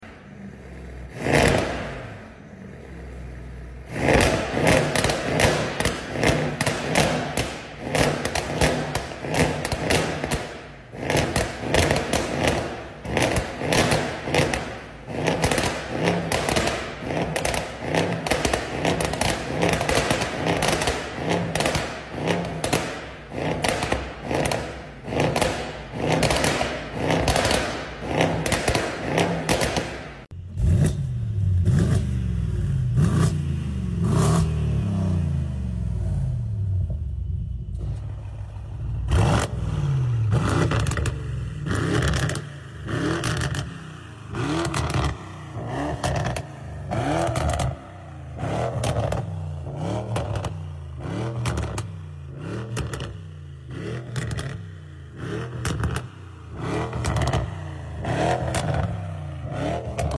Mercedes V8 Battle, S63 Or Sound Effects Free Download